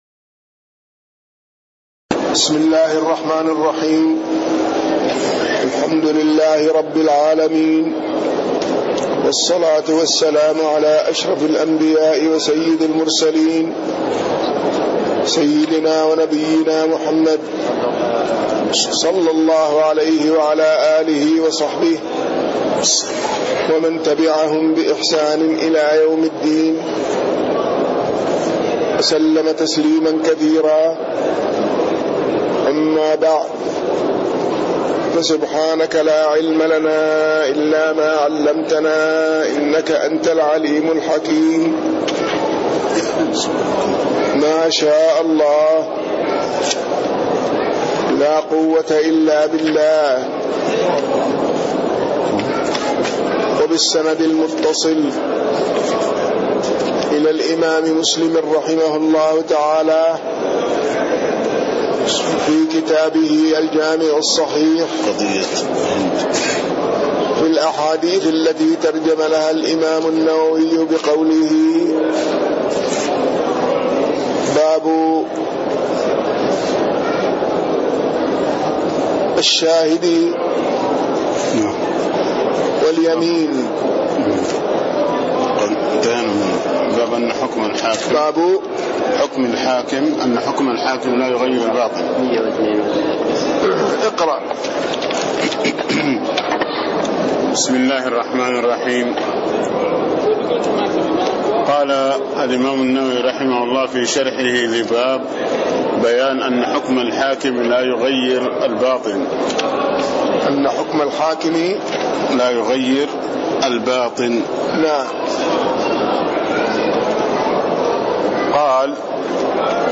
تاريخ النشر ٥ شعبان ١٤٣٥ هـ المكان: المسجد النبوي الشيخ